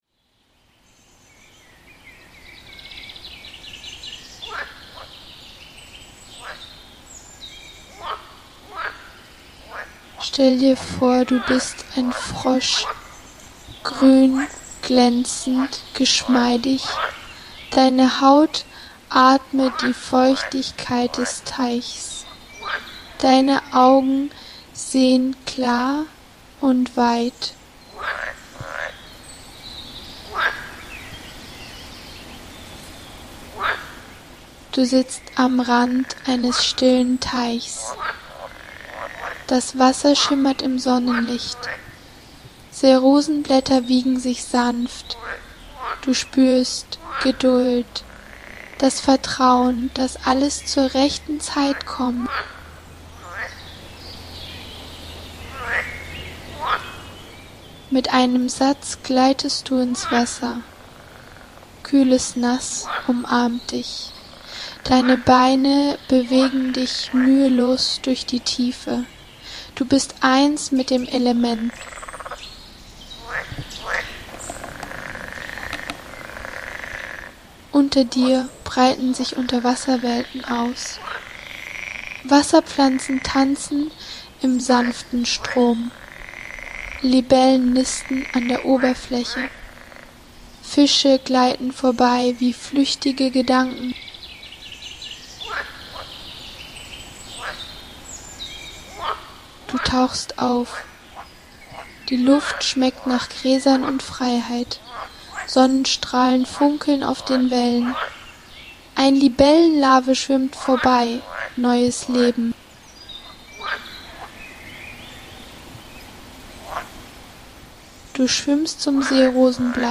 Tauche ein in eine geführte Meditation als geduldiger Frosch an einem stillen Teich.